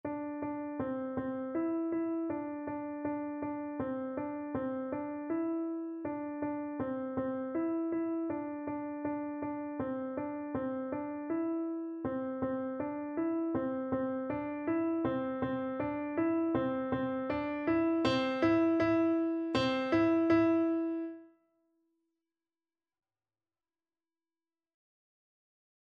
2/4 (View more 2/4 Music)
Piano  (View more Beginners Piano Music)
Classical (View more Classical Piano Music)